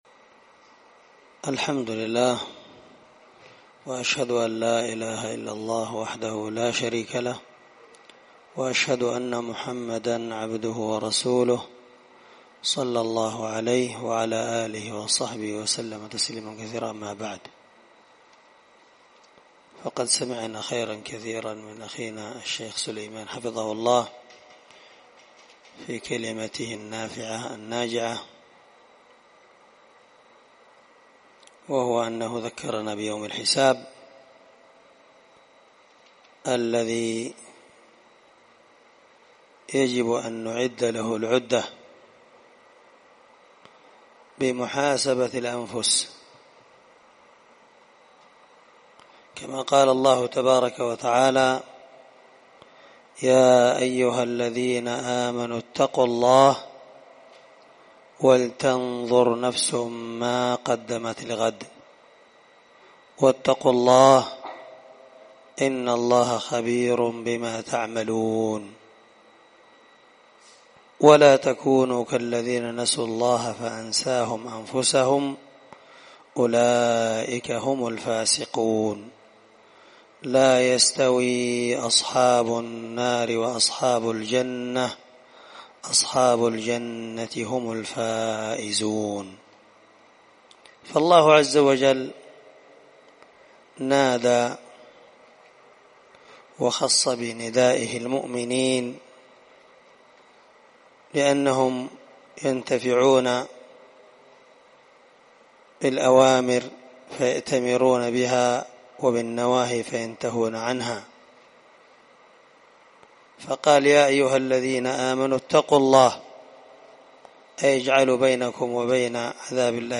خطبة بعنوان الظلم وخطرة